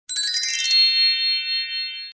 알림음 8_윈드벨.ogg